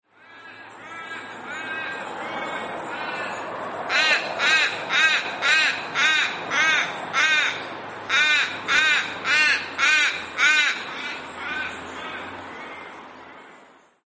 Little Raven - Corvus mellori
Voice: harsh barking call, notes mostly cut off but sometimes trailing or dying away.
Call 1: abrupt calls
Call 2: dying calls
Little_Raven_nark.mp3